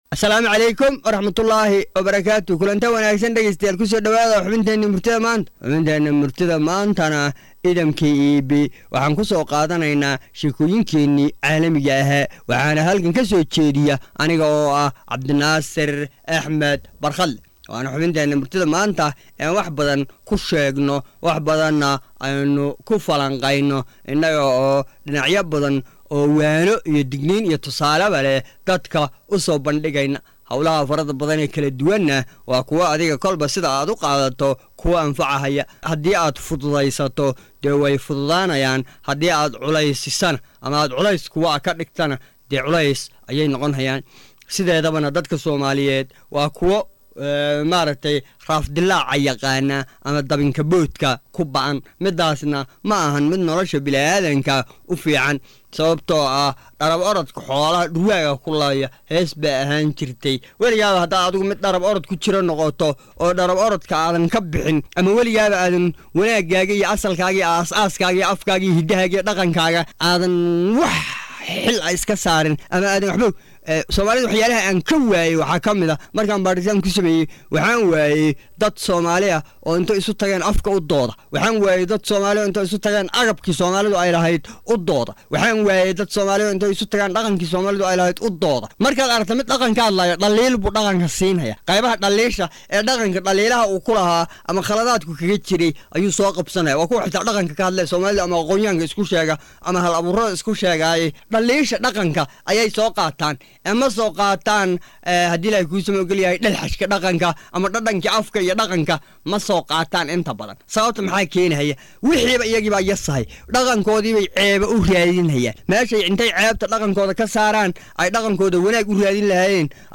Soo jeedinta abwaan